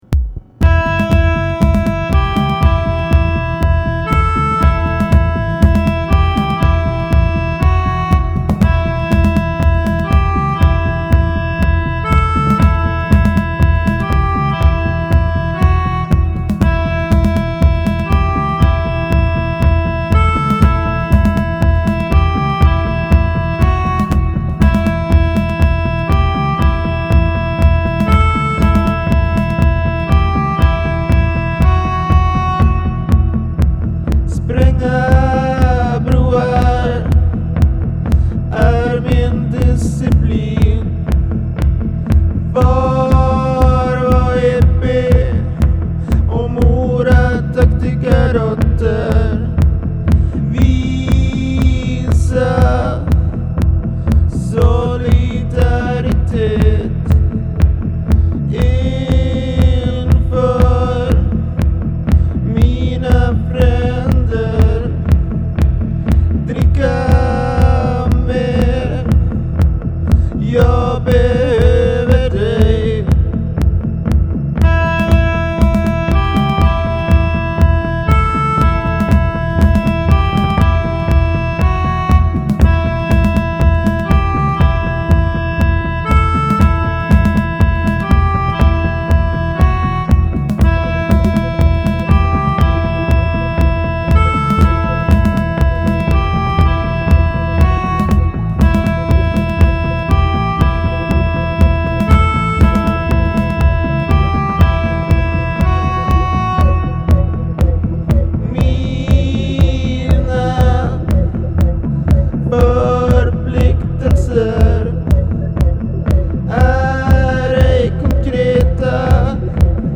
much weirder